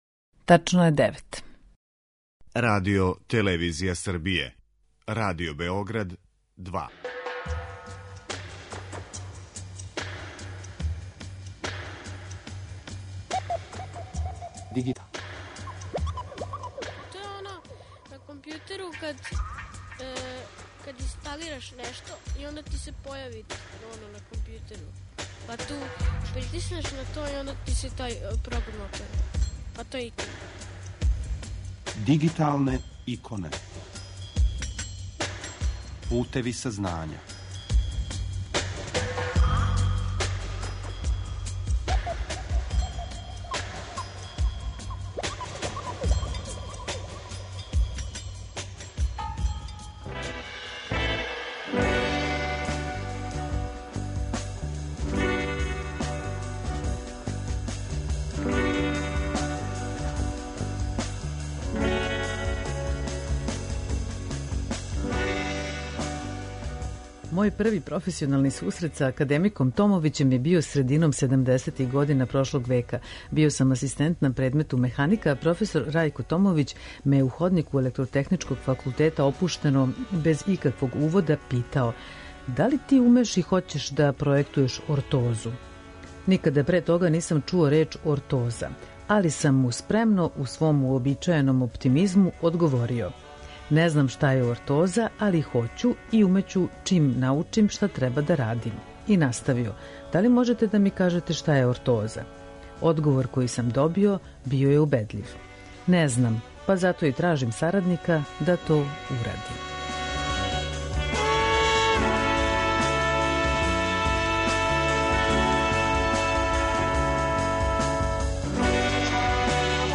а емитује се сваког уторка на таласима Радио Београда 2 од 9 до 10 сати.